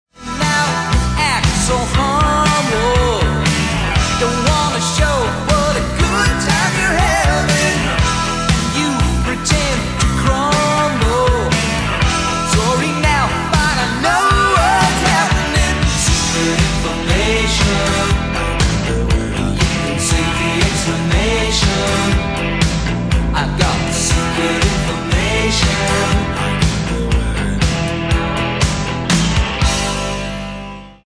guitar, drums, keyboards, vocals
bass